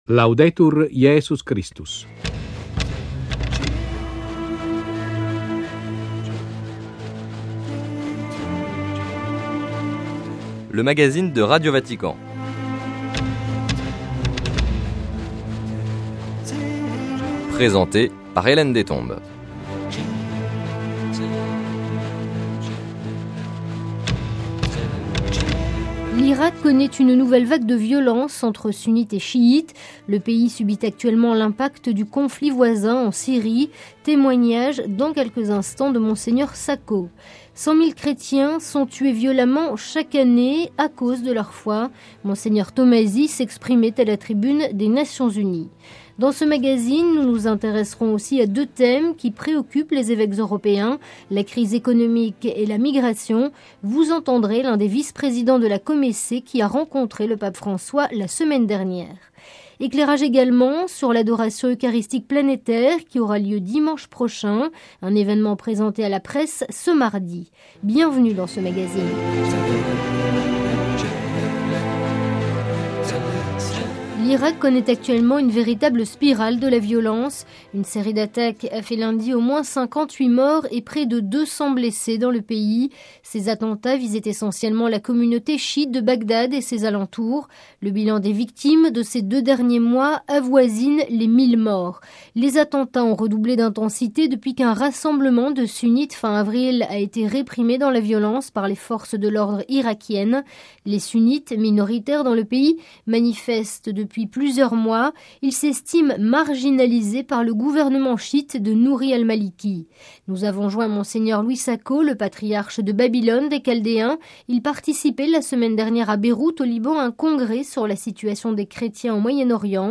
Témoignage de Mgr Sako, Patriarche de Babylone des Chaldéens. - Cent mille chrétiens sont tués violemment chaque année à cause de leur foi. Intervention de Mgr Tomasi, à la tribune des Nations Unies.
Entretien avec Mgr Jean Kockerols, l’un des vice-présidents de la Comece.